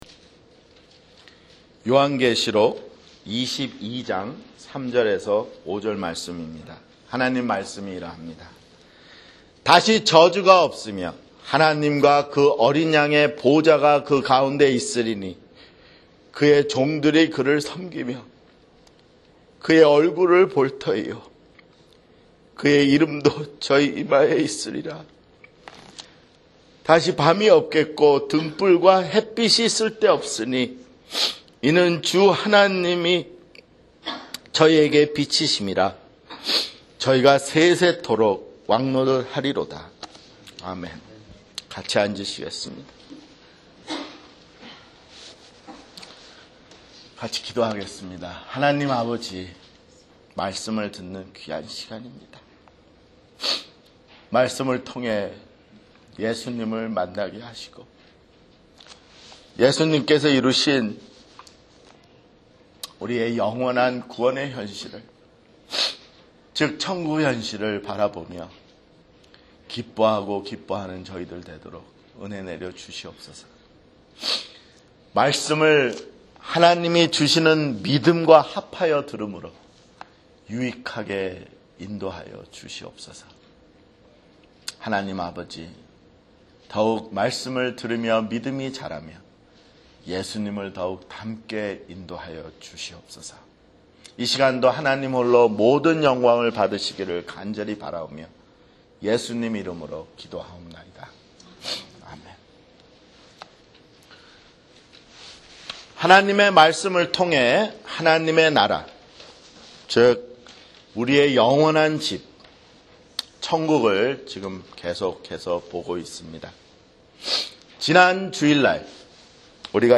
[주일설교] 요한계시록 (90)